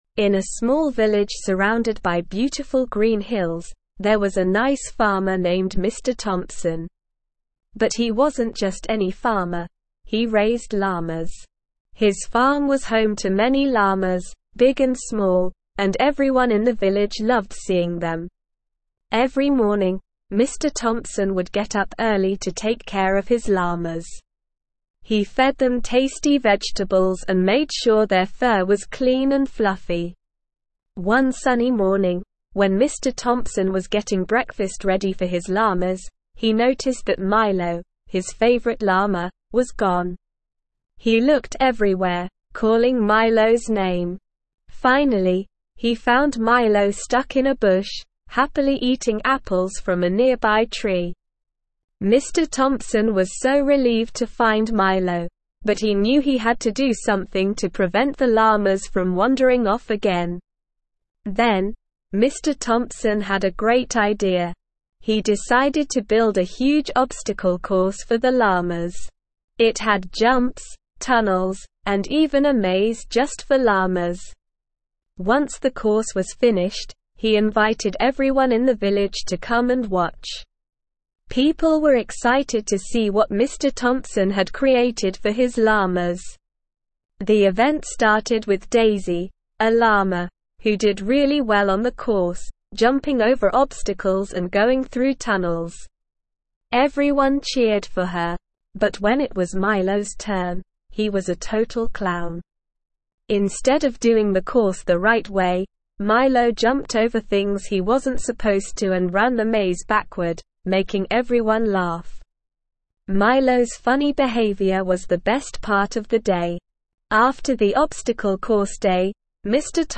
Slow
ESL-Short-Stories-for-Kids-Upper-Intermediate-SLOW-Reading-The-Llama-Farmer.mp3